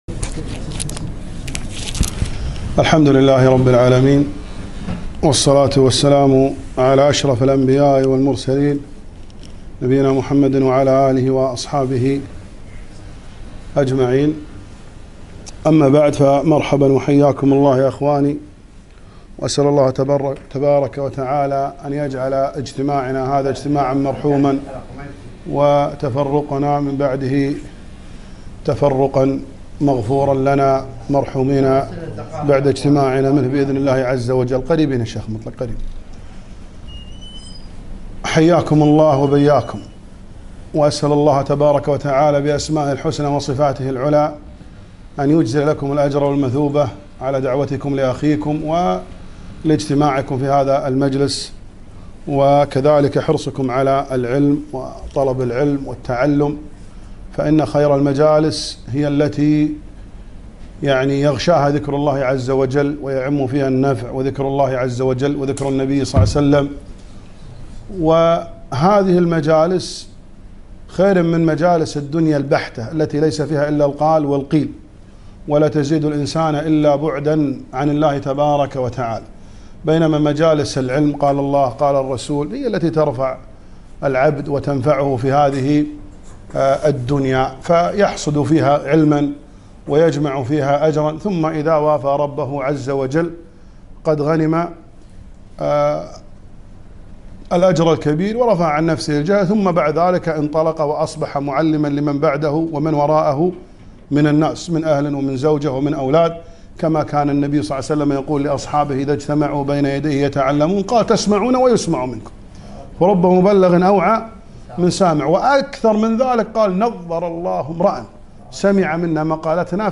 محاضرة - فضل العلم وأهمية تقييده وحفظه